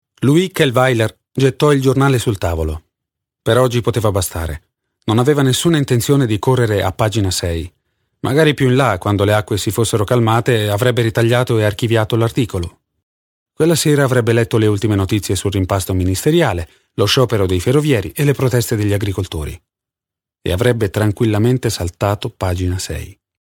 Sprecher italienisch.
Sprechprobe: eLearning (Muttersprache):